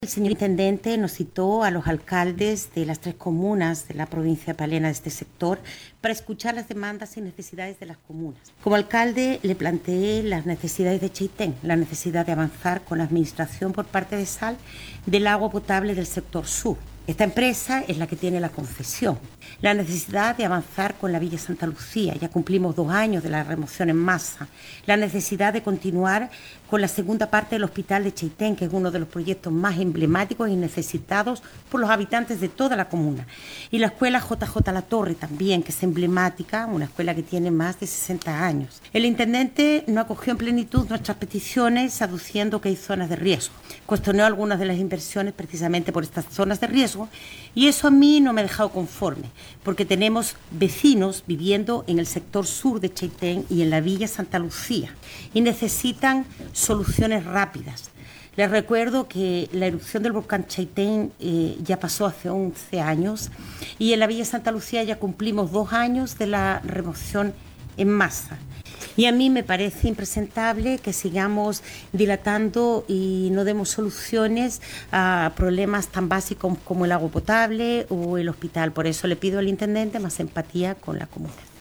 11 años han pasado de la erupción del volcán Chaitén y dos años del aluvión de Villa Santa Lucía y aún hay vecinos con problemas de agua potable y no se cuenta con un hospital que atienda efectivamente a los habitantes de la zona, dijo muy sentida la alcaldesa de Chaitén, Clara Lazcano.
09-ALCALDESA-CHAITEN-CLARA-LAZCANO.mp3